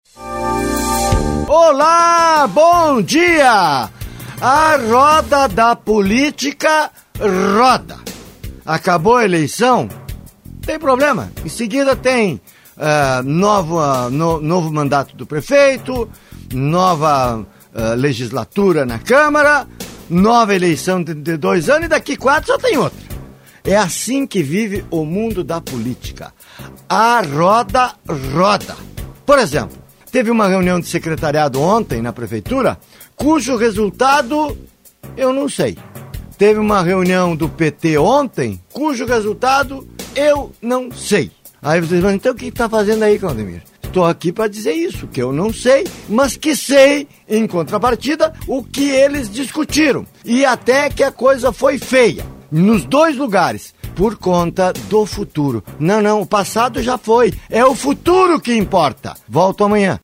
COMENTÁRIO DO DIA. Sim, a roda da política roda. Que o digam petistas e peemedebistas.